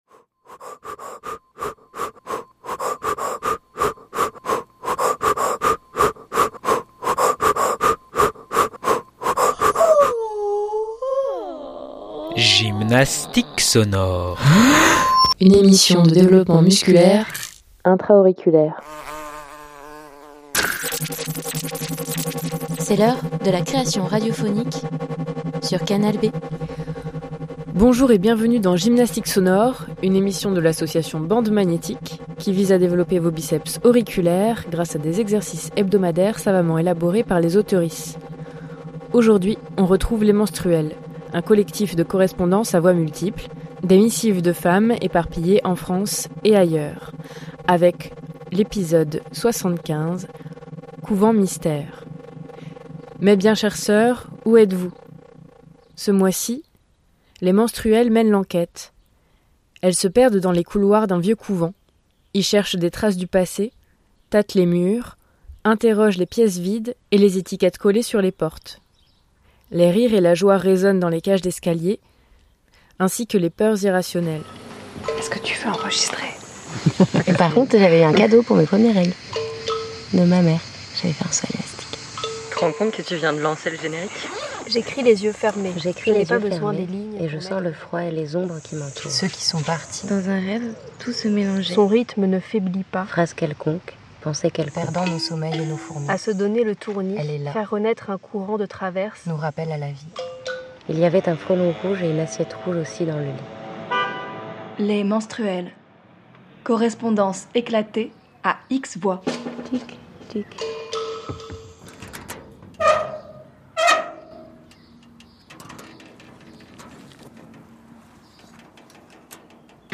Les Menstruelles #75 - Couvent Mystère 21/06/2025 60 mn Les Menstruelles , c'est un collectif de correspondances à voix multiples.
Elles se perdent dans les couloirs d'un vieux couvent, y cherchent des traces du passé, tâtent les murs, interrogent les pièces vides et les étiquettes collées sur les portes. Les rires et la joie résonnent dans les cages d'escalier, ainsi que les peurs irrationnelles.